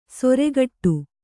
♪ soregaṭṭu